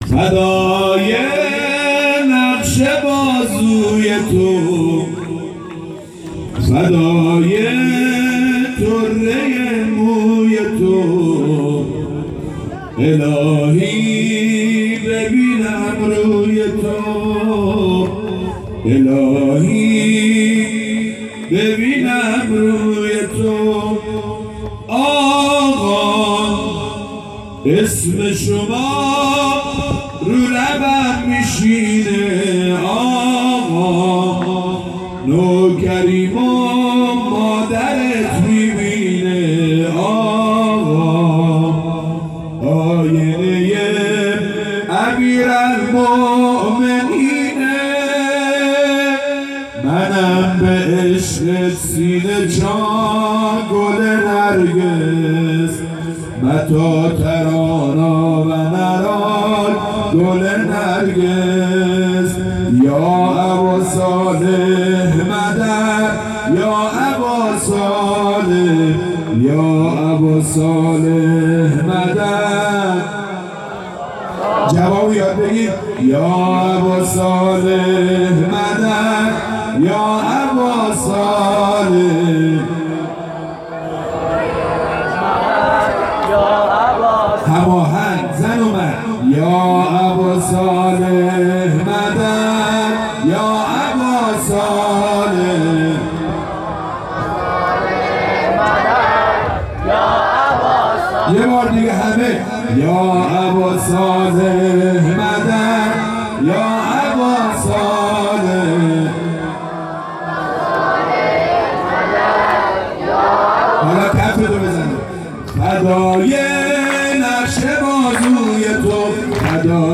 مراسم جشن بزرگ نیمه شعبان98هیئت میثاق الحسین علیه السلام سیستان